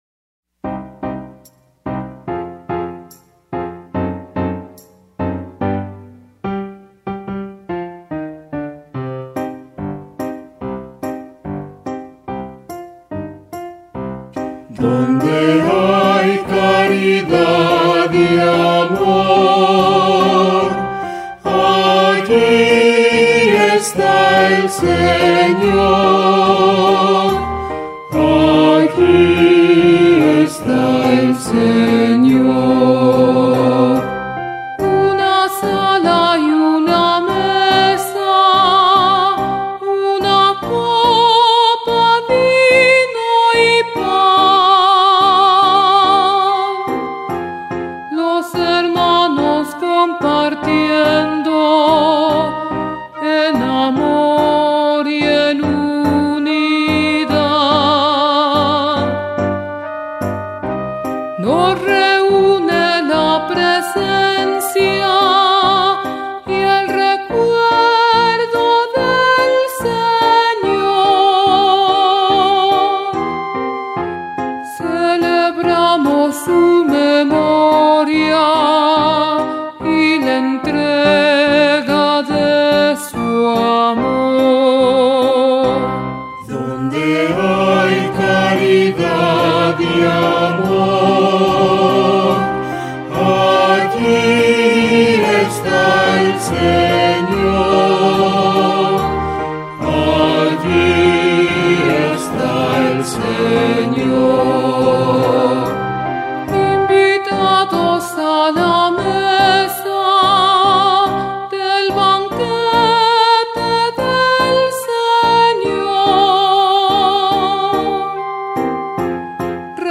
Cantos Litúrgicos